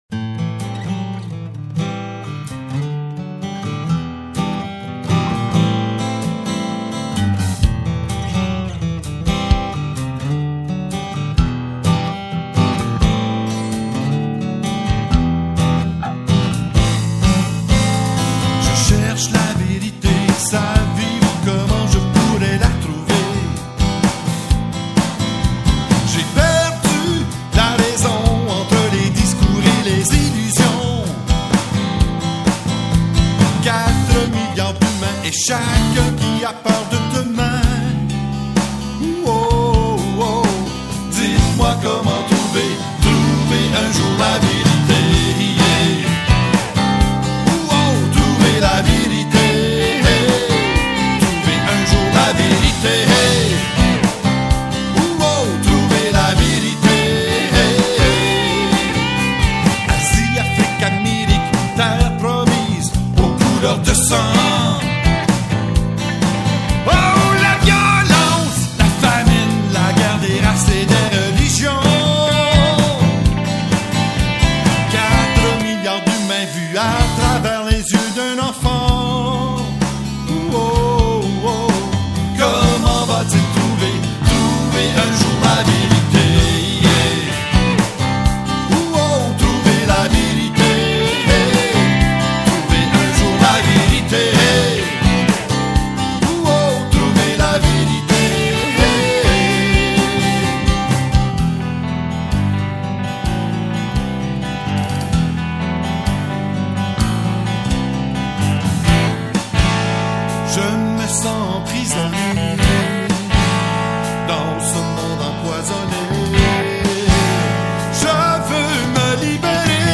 Chants divers
trouver_la_verite_chant.mp3